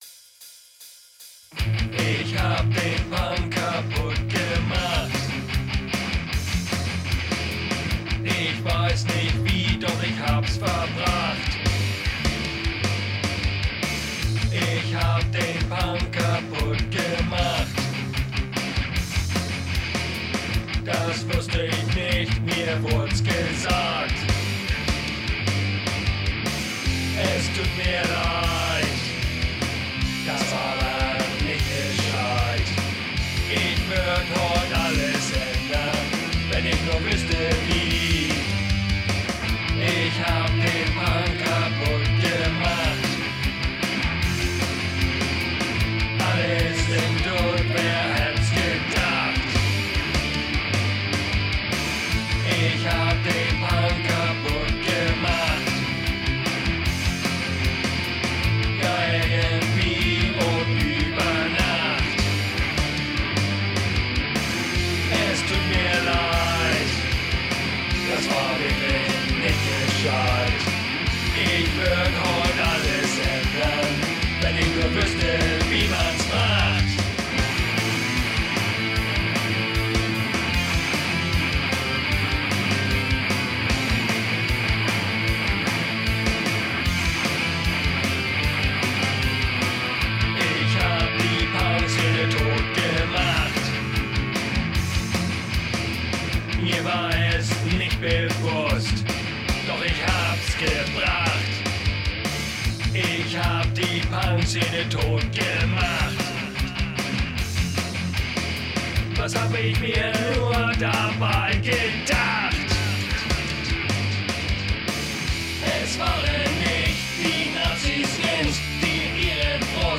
low quality web version
Lead Vocals
Doom Bass
Death Drums